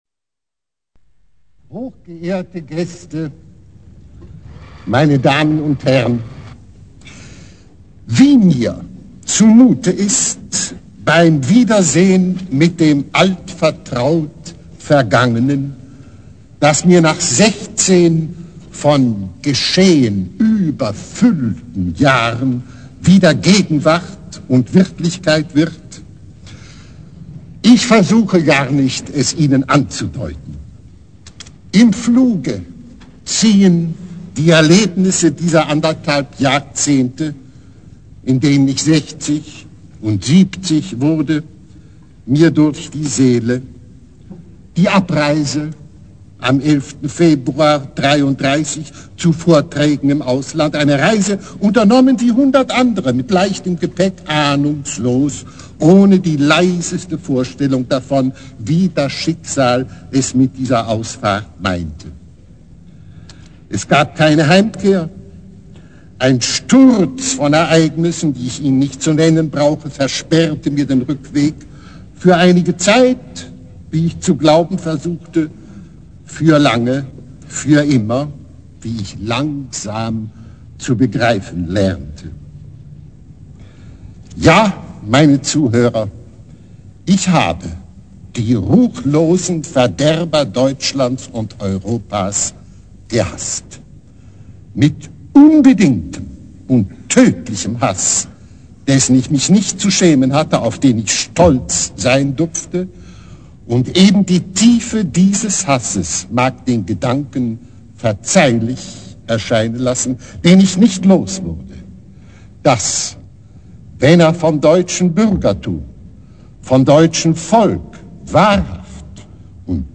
1949er Rede zu seiner erzwungenen Auswanderung